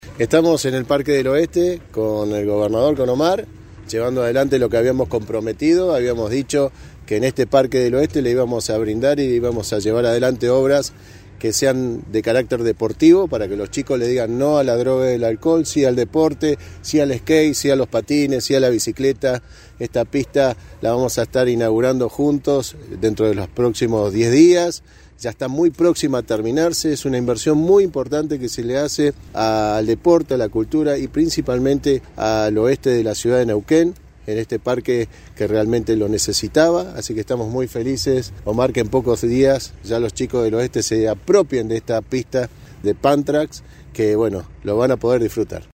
Escuchar audio. Mariano Gaido, Intendente.